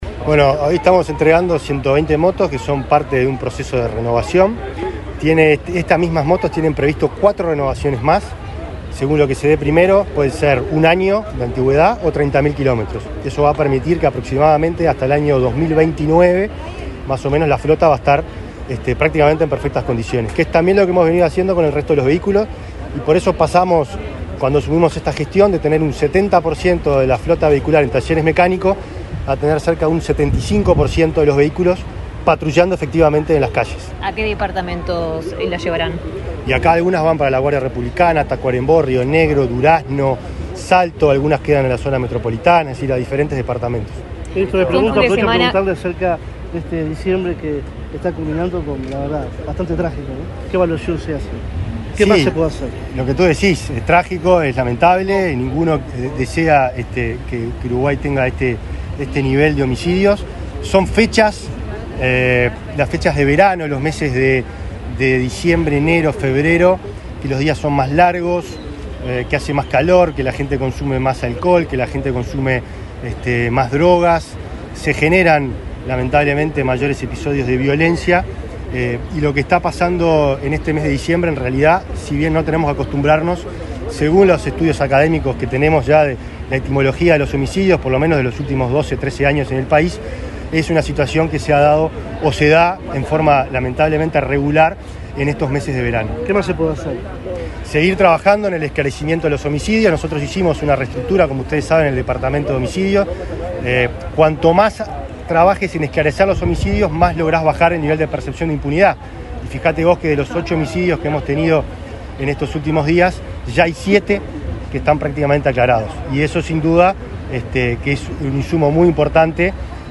Declaraciones del ministro del Interior, Nicolás Martinelli
Declaraciones del ministro del Interior, Nicolás Martinelli 27/12/2024 Compartir Facebook X Copiar enlace WhatsApp LinkedIn Este viernes 27, en Montevideo, el ministro del Interior, Nicolás Martinelli, dialogó con la prensa, luego de participar en el acto de entrega de 120 nuevas motos, que se incorporan a la flota de distintas jefaturas del país y direcciones nacionales.